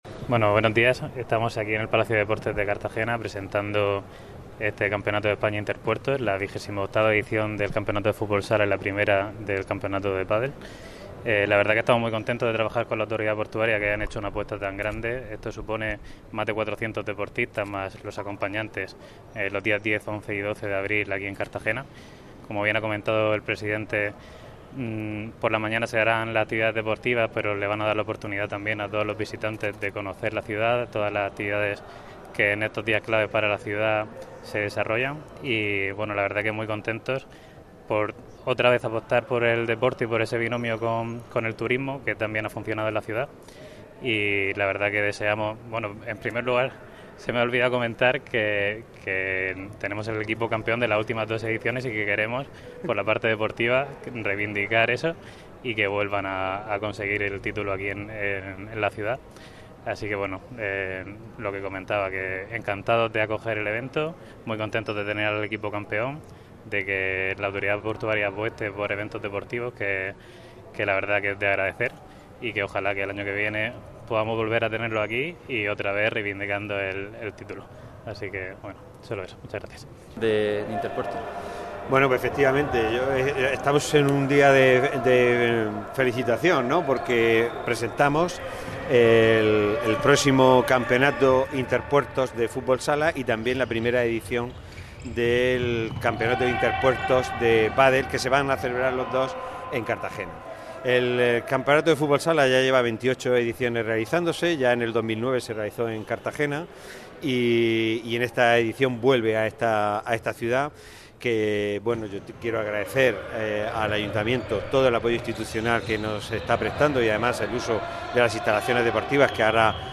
Esta cita ha sido presentado por el concejal de Deportes, José Martínez, y el presidente de la Autoridad Portuaria de Cartagena, Pedro Pablo Hernández.